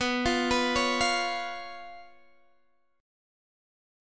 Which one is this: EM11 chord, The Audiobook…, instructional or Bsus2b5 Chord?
Bsus2b5 Chord